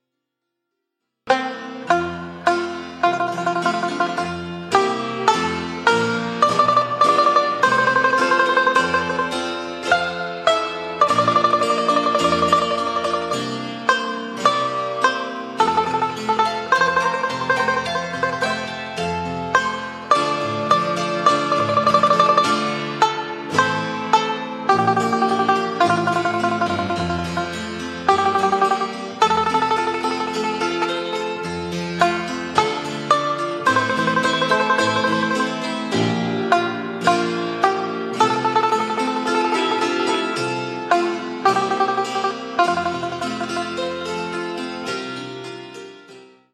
Old Adventure Folk Song, Unknown Origin
8-beat intro.